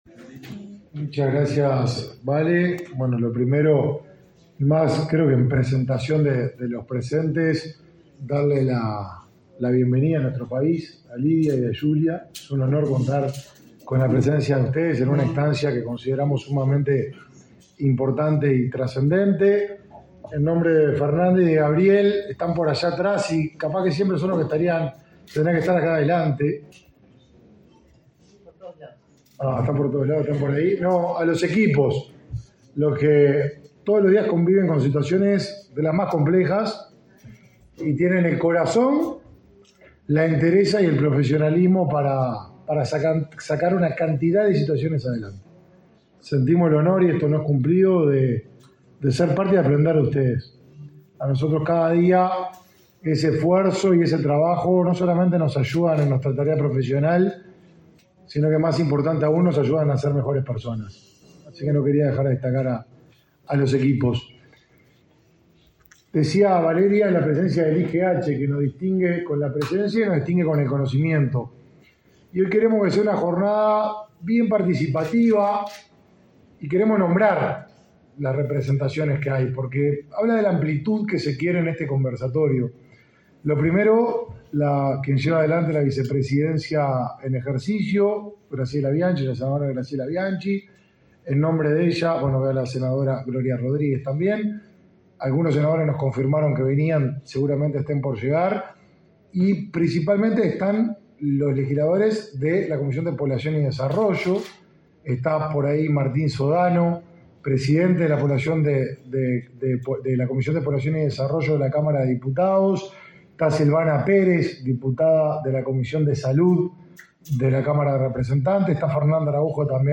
Declaraciones a la prensa del titular del Mides, Martín Lema